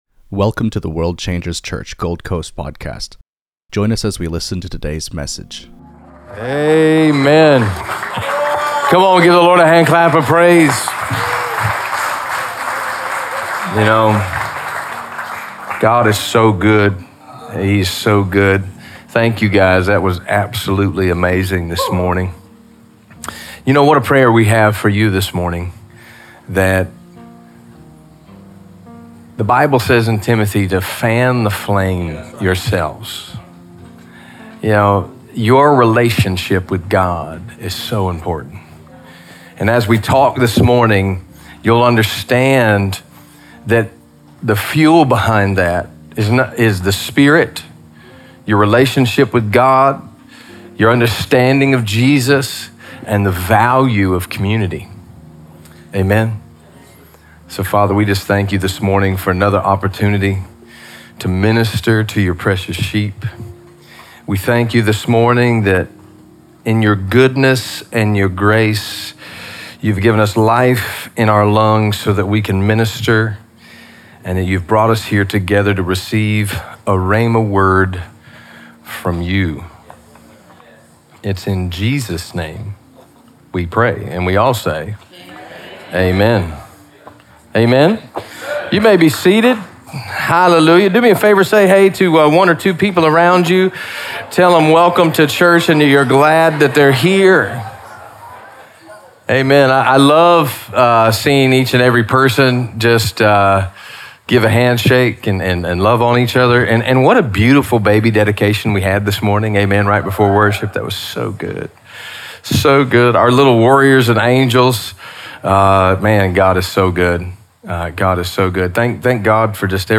Emphasizing holiness, righteousness, and the renewal of the mind, this sermon challenges the church to move beyond man-made traditions and embrace a Christ-centered life.